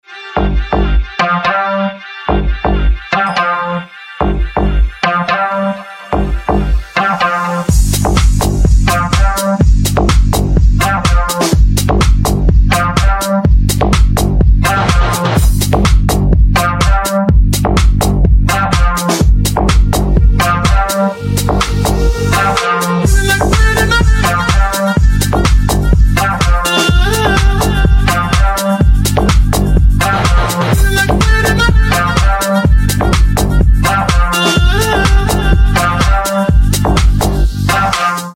• Качество: 320, Stereo
ритмичные
Electronic
EDM
house